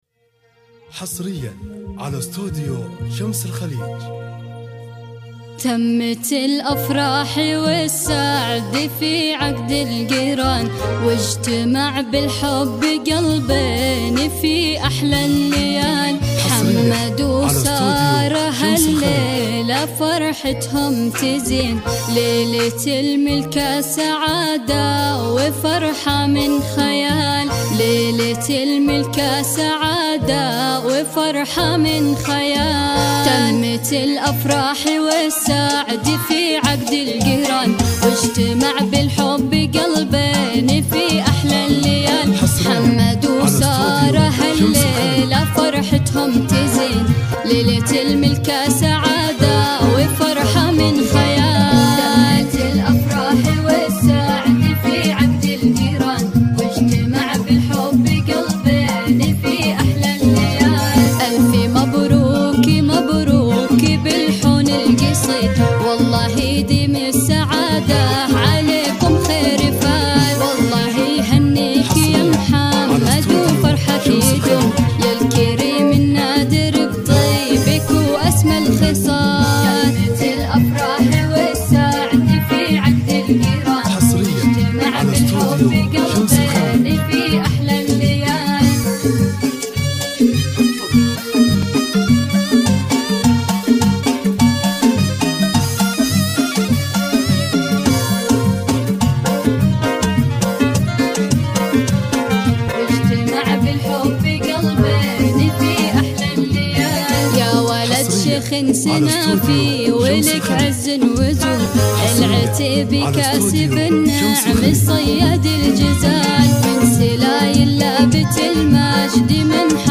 زفات موسيقى – زفات كوشة